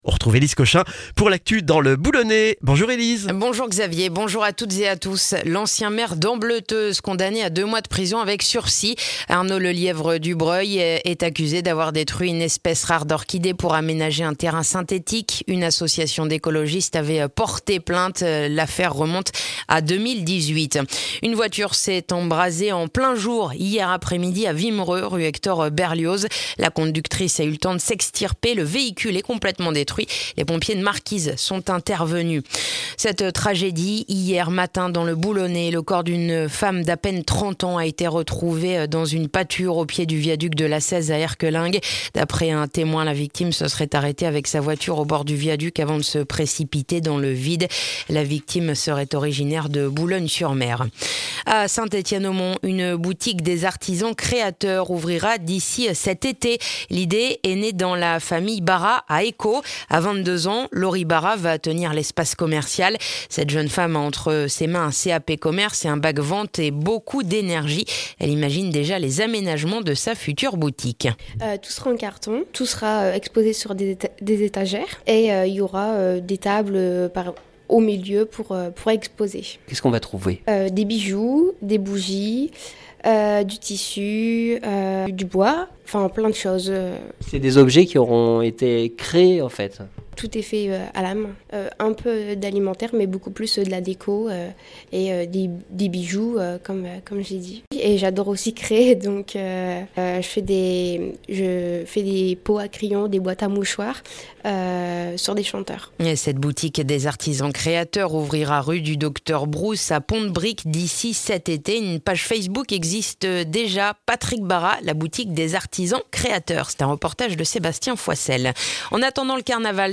Le journal du jeudi 24 mars dans le boulonnais